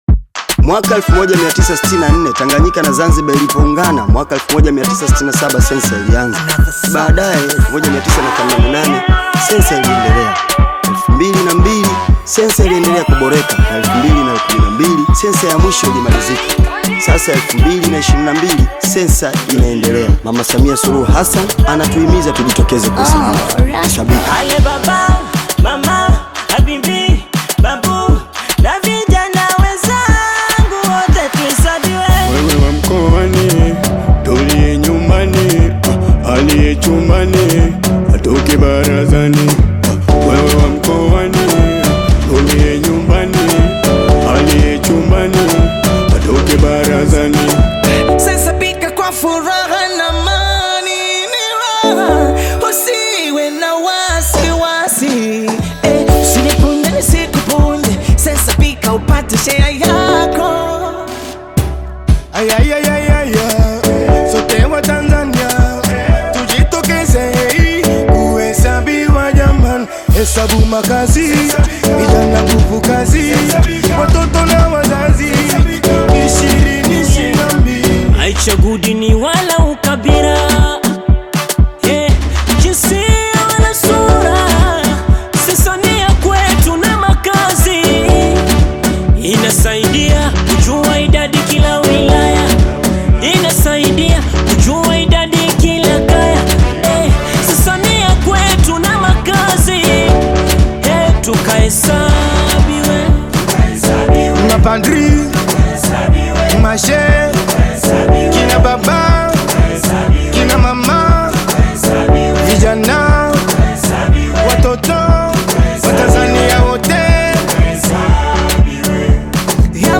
Tanzanian band of five
African Music